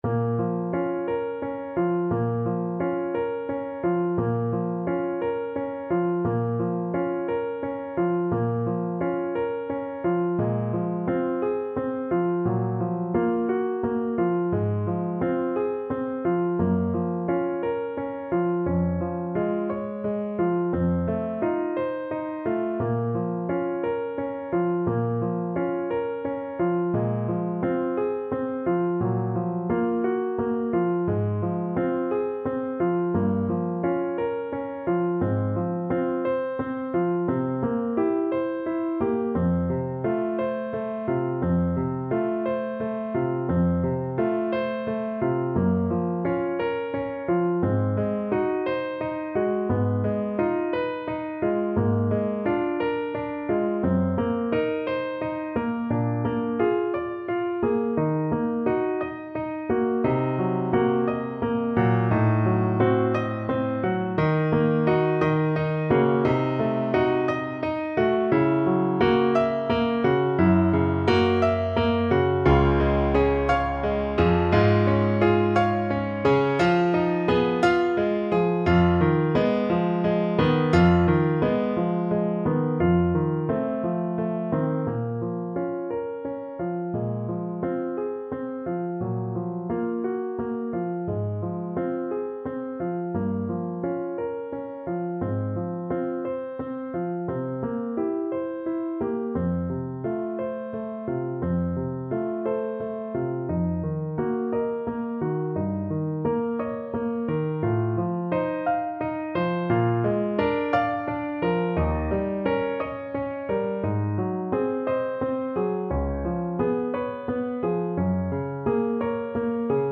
12/8 (View more 12/8 Music)
Andante .=58